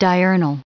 953_diurnal.ogg